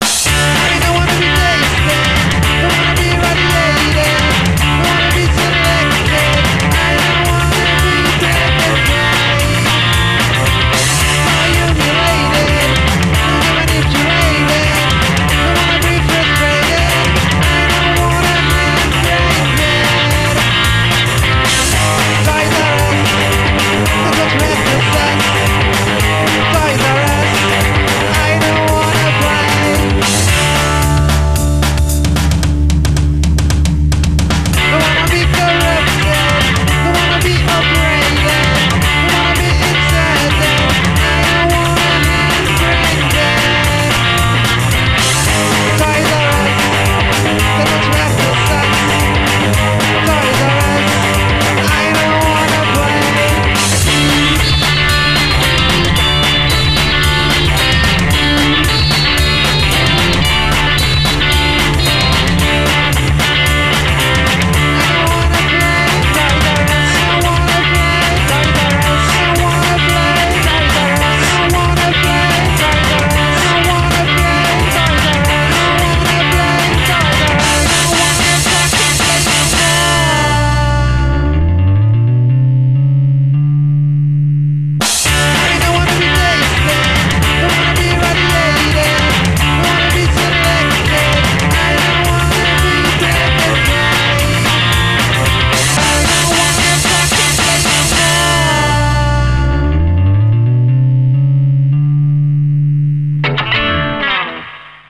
Démos